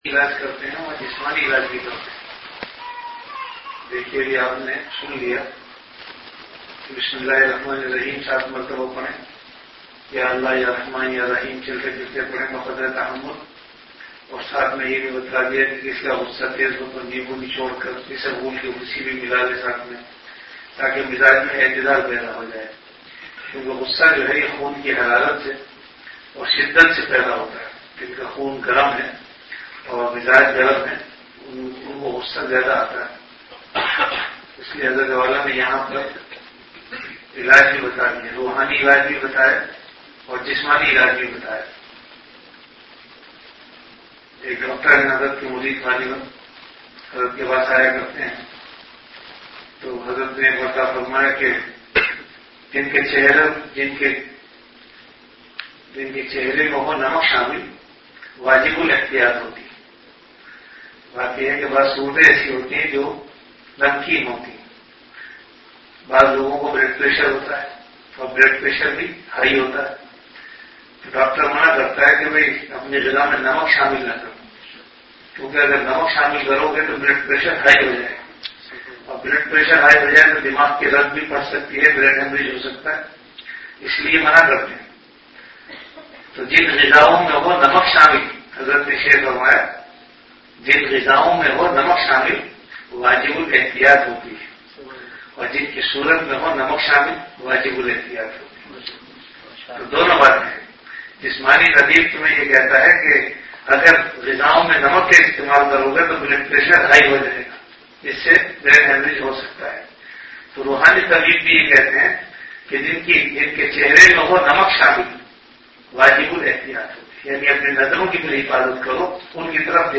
An Islamic audio bayan
Delivered at Home.
After Isha Prayer